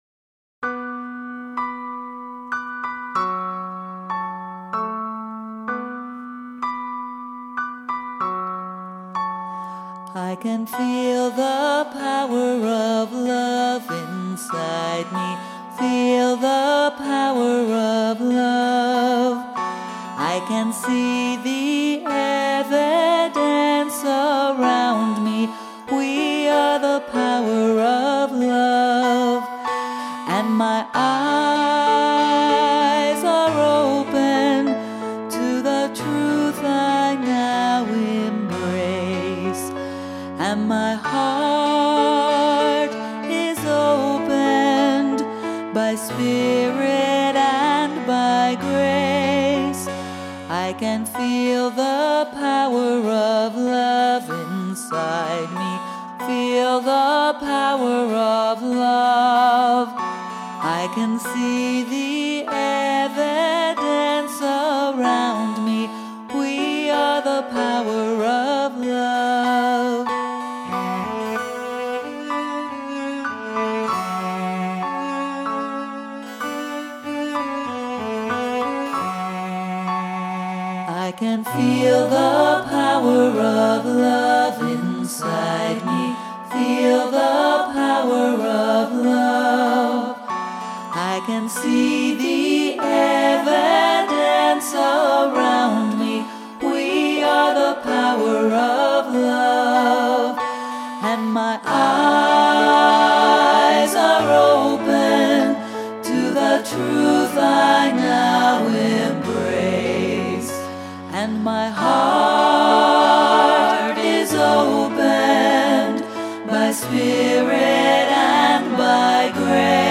So I started writing a simple little tune and it hit the spot. I love the rich harmony in this song.